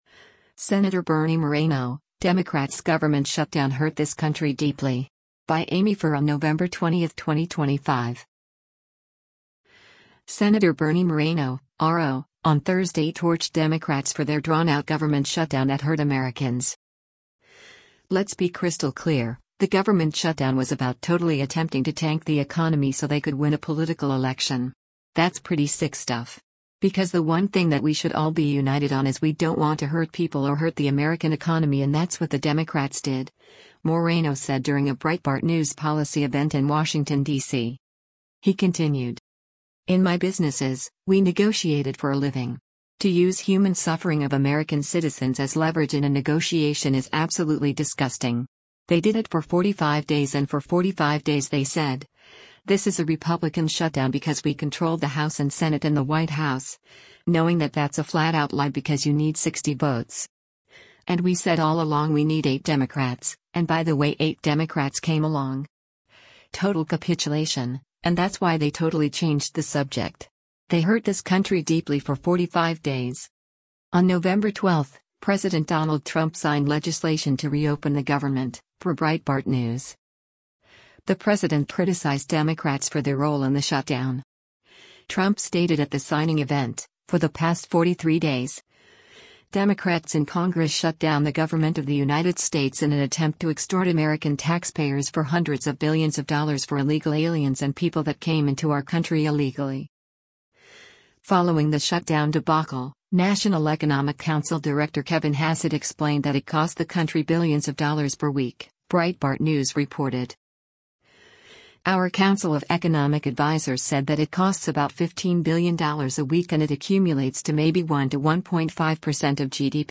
“Let’s be crystal clear, the government shutdown was about totally attempting to tank the economy so they could win a political election. That’s pretty sick stuff. Because the one thing that we should all be united on is we don’t want to hurt people or hurt the American economy and that’s what the Democrats did,” Moreno said during a Breitbart News policy event in Washington, DC.